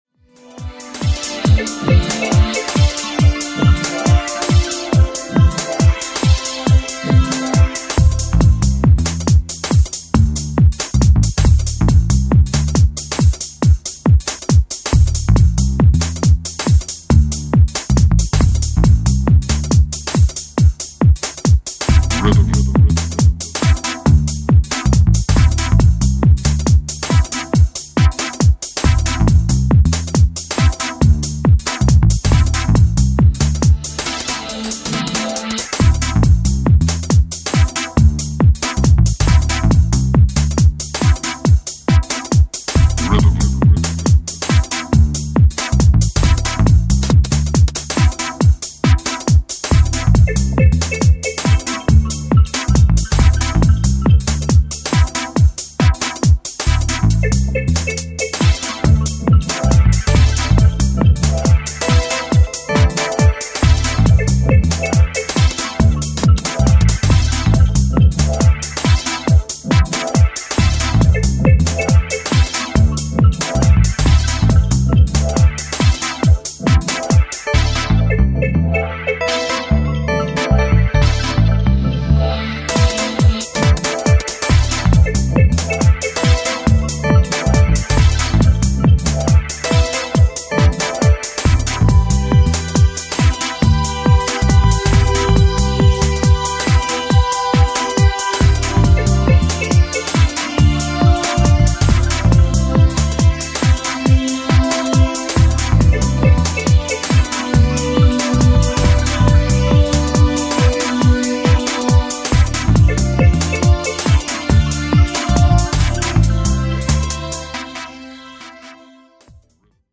jacked out swing and soulful vibe
more atmospheric